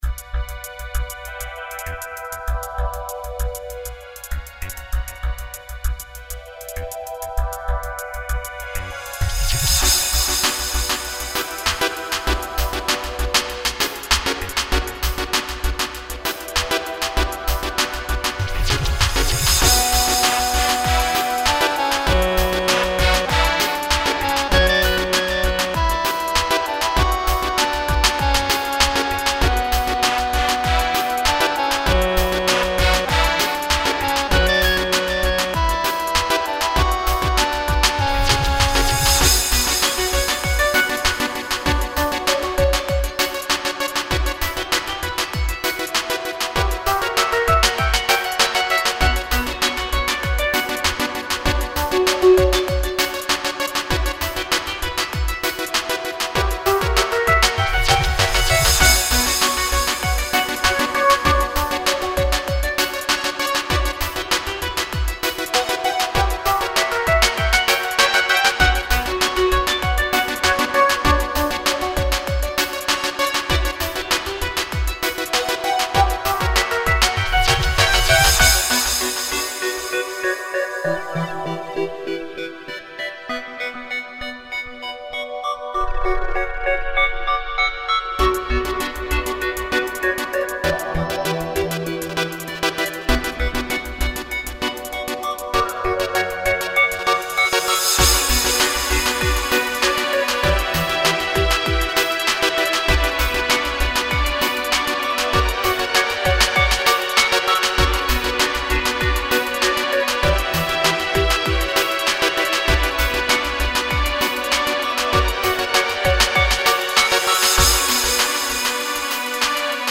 За сведение сильно не пинайте: сводил в наушниках Technics за 1000 р. Расстроен
В общем и целом - мне как слушателю понравилось, но такое ощущение, что в спешке делал, уж больно переходы поджаты, да и темп какой-то дикий.
Что касается темпа - просто экспереметировал с hip hop'ом Улыбка
Русско-народные опевания в мелодии, и гармония оттуда же...
Гармошка, брассы...
Лично мне самому мелодия напоминает скорее музыку из советских кинофильмов.
Короткие фразы на 2 такта с повтором для коды в тонику.